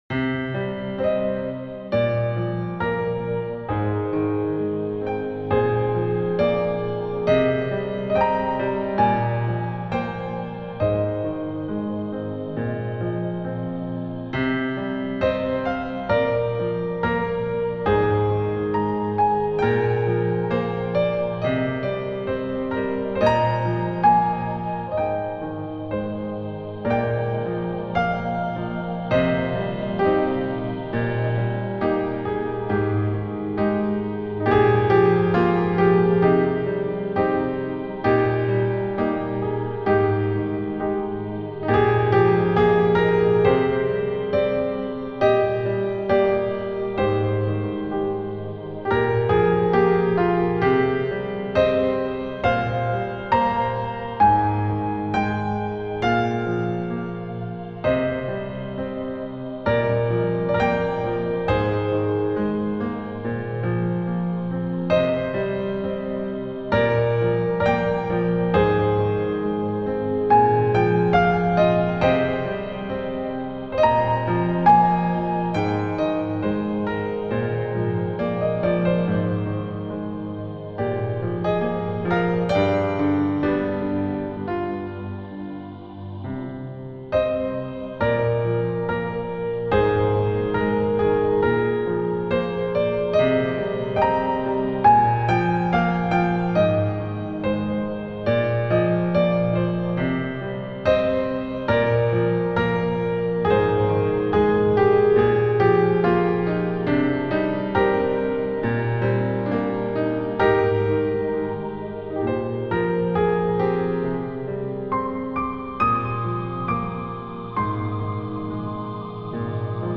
ژانر: بی کلام